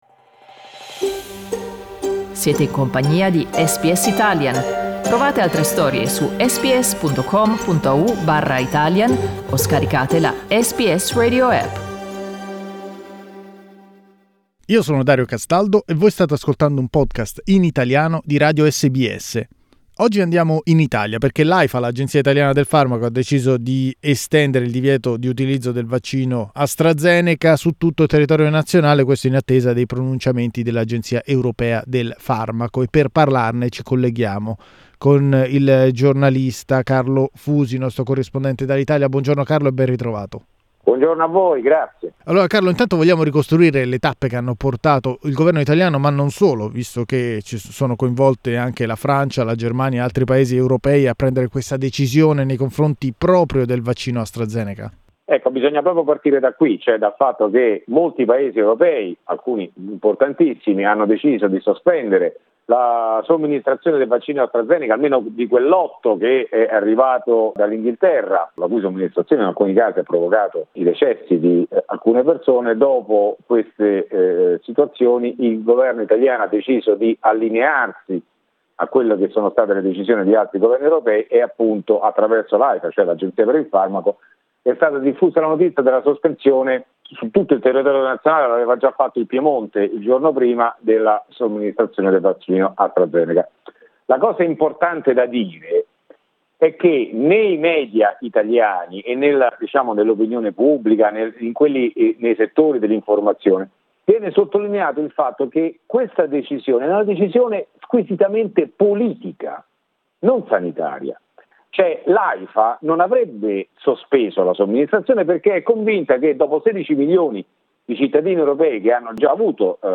Riascolta il resoconto del giornalista